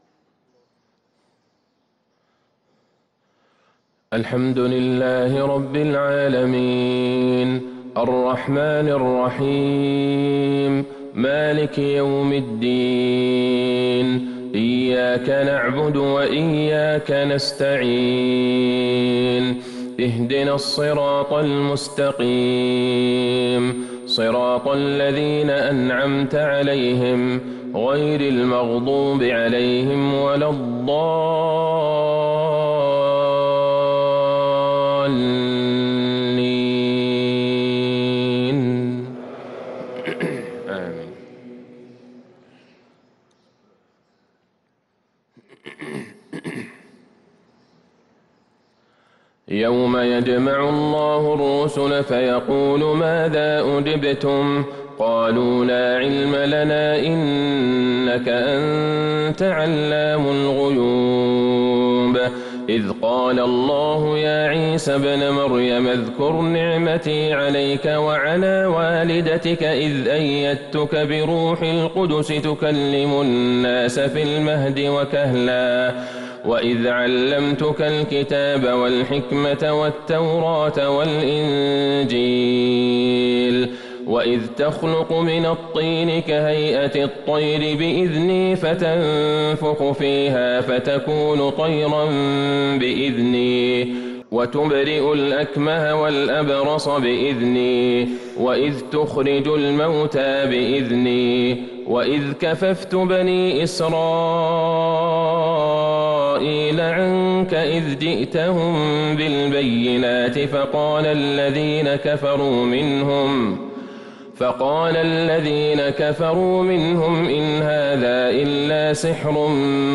فجر الأحد ٦ جمادى الآخرة ١٤٤٣هـ | خواتيم المائدة ١٠٩-١٢٠ | Fajr prayer from Surah Al-Maedaah 9-1-2022 > 1443 🕌 > الفروض - تلاوات الحرمين